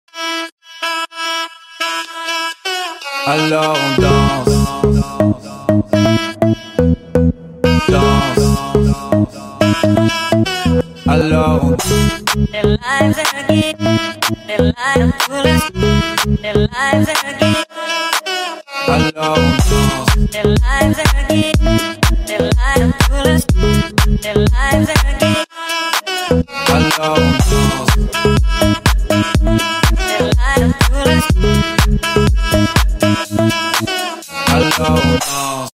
Громкие Рингтоны С Басами
Рингтоны Ремиксы » # Рингтоны Электроника